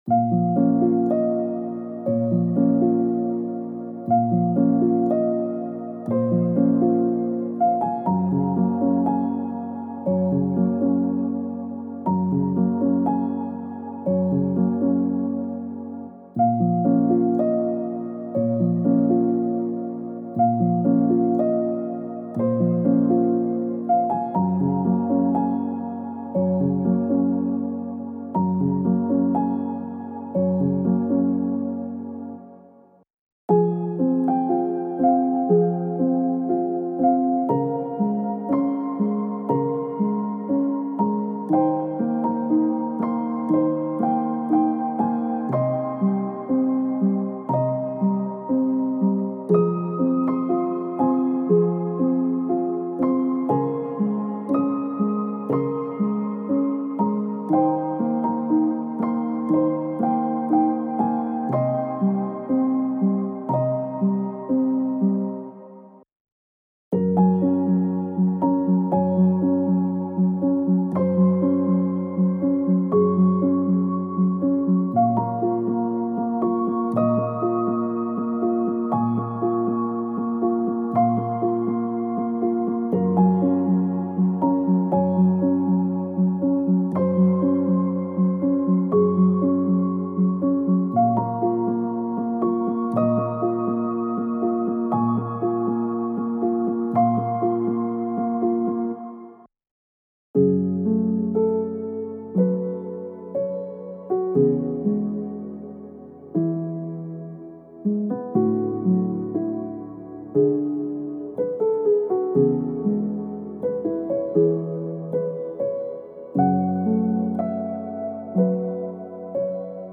Type: Midi Samples
15 MIDI KITS ( 15 Piano MIDI in total )